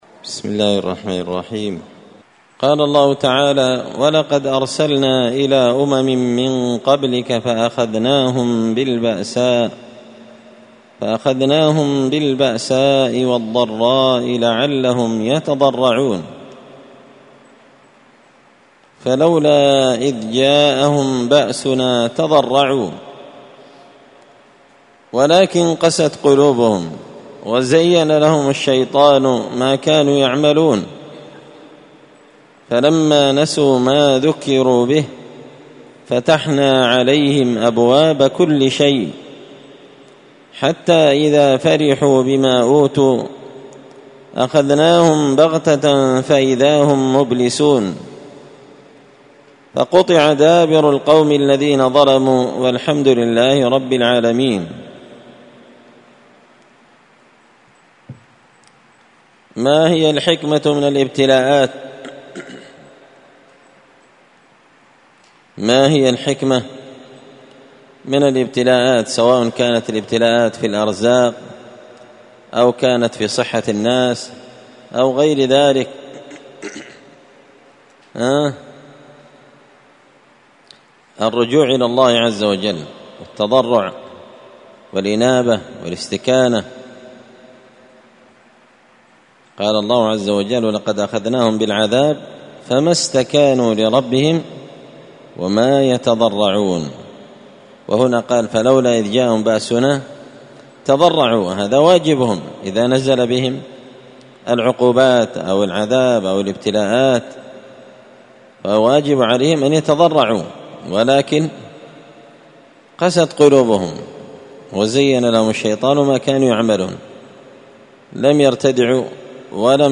مختصر تفسير الإمام البغوي رحمه الله الدرس 310